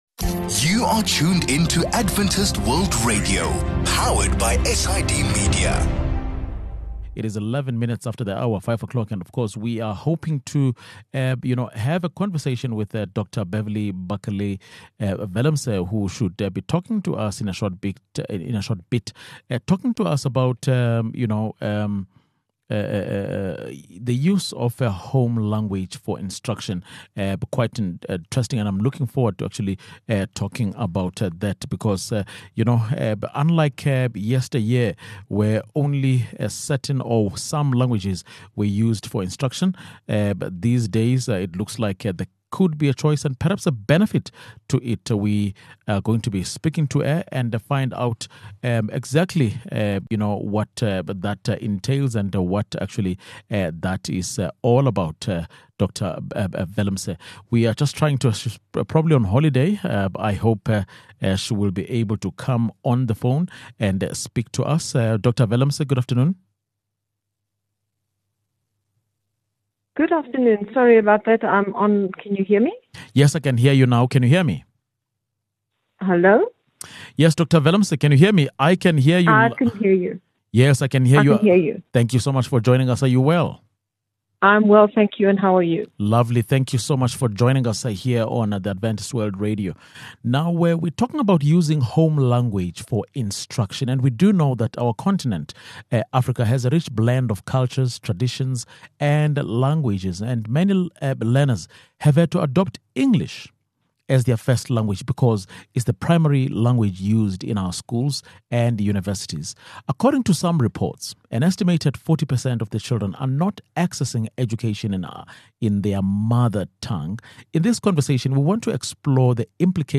In this conversation, we want to explore the implications of using a learner’s home language for instruction in school. In a country as culturally and linguistically diverse as South Africa, what would this look like?